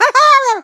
gene_vo_14.ogg